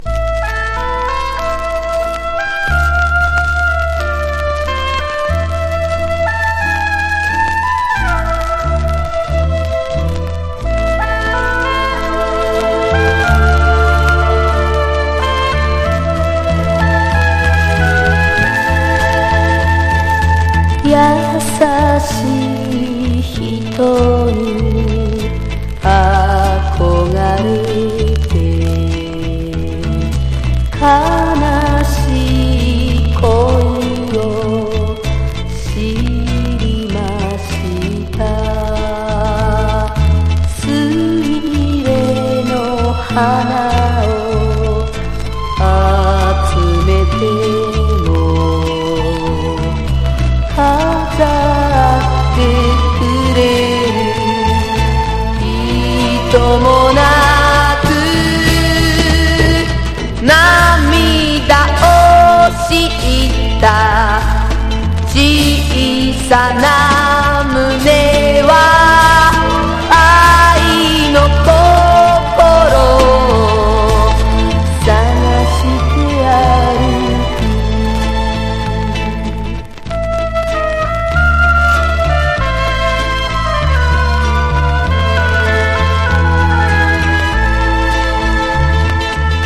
和モノ / ポピュラー# 70-80’S アイドル